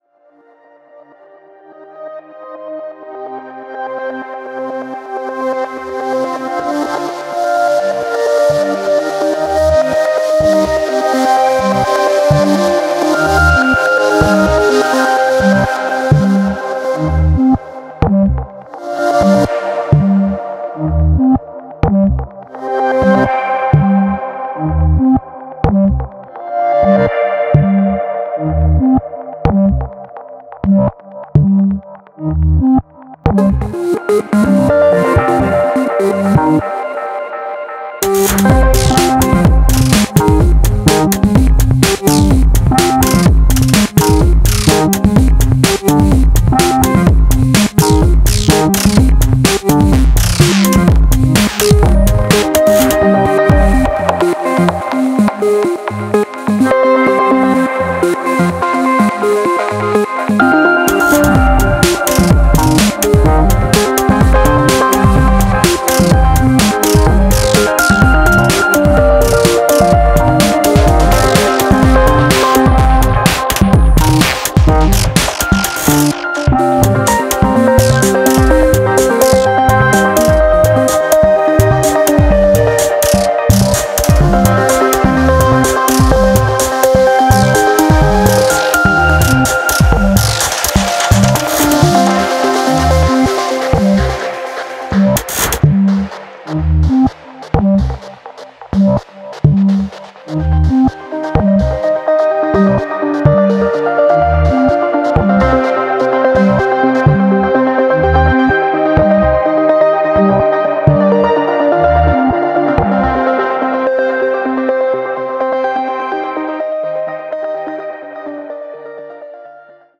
BPM126
Audio QualityPerfect (High Quality)
A smooth and psychedelic-sounding ambient techno song